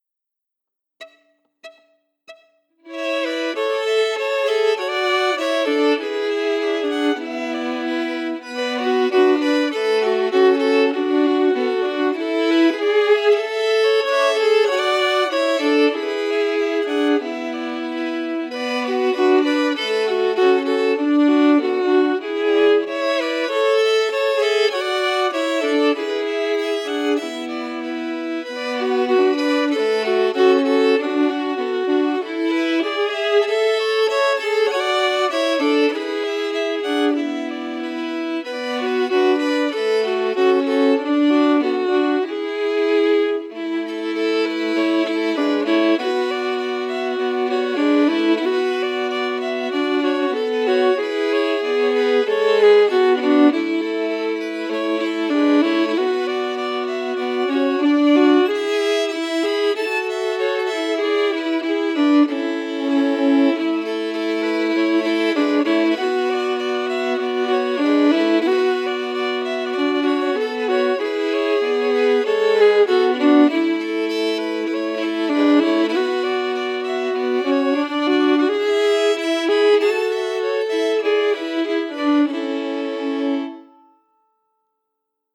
Key: A
Form: Slow reel
Harmony emphasis